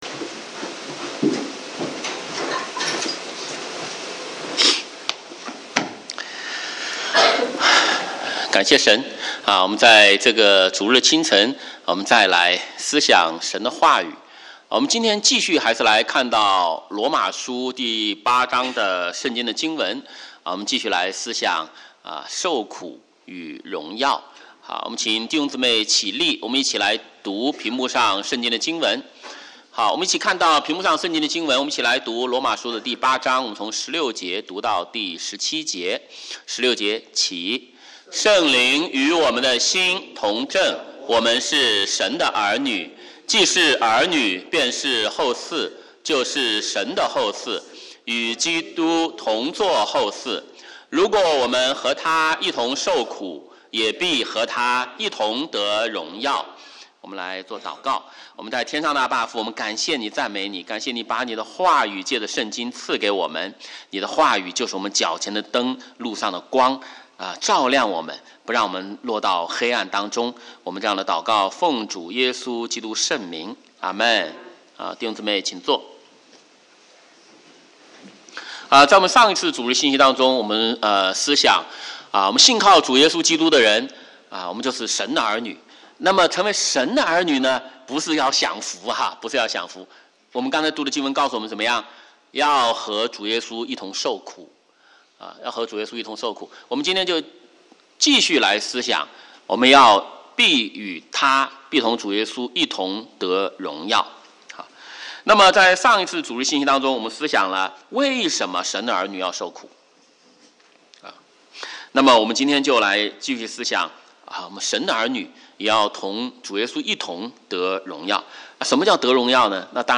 华语主日崇拜讲道录音